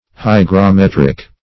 Search Result for " hygrometric" : The Collaborative International Dictionary of English v.0.48: Hygrometric \Hy`gro*met"ric\, Hygrometrical \Hy`gro*met"ric*al\, a. [Cf. F. hygrom['e]trique.] 1.
hygrometric.mp3